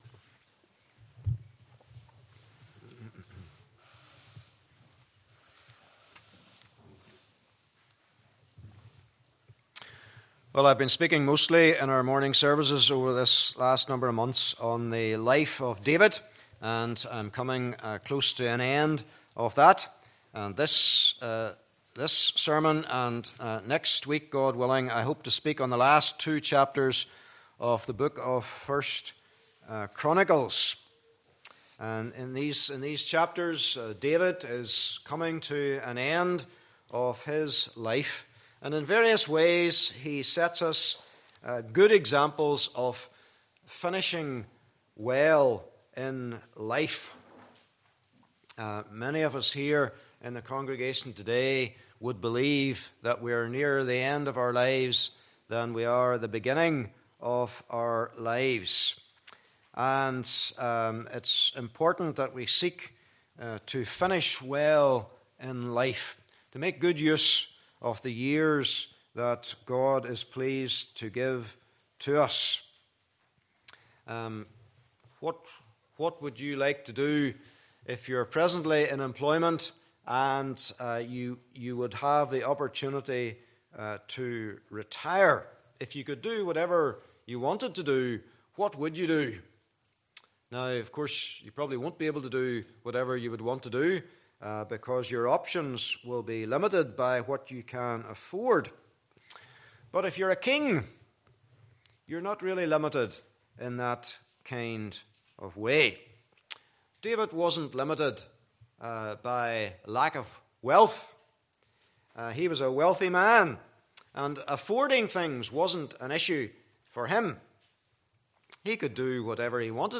Passage: 1 Chronicles 28: 1 - 21 Service Type: Morning Service